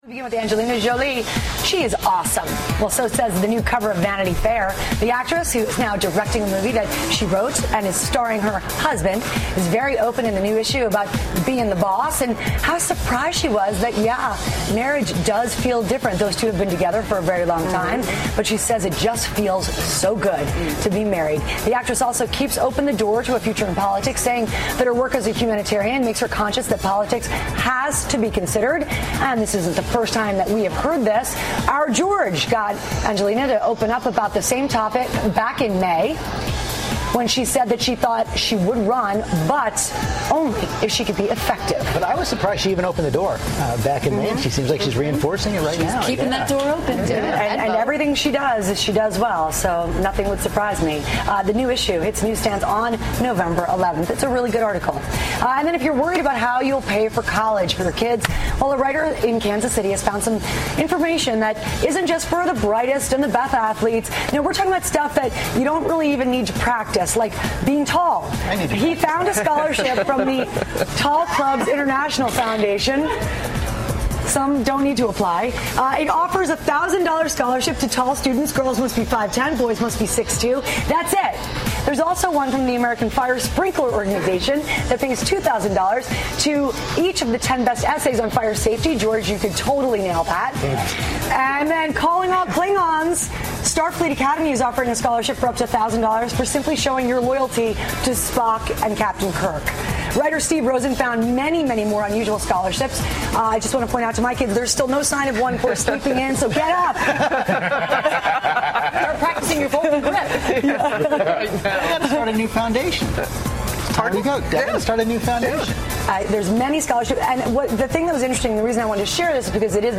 访谈录 2014-11-10&11-12 安吉丽娜·朱莉或从政 首先参与慈善事业 听力文件下载—在线英语听力室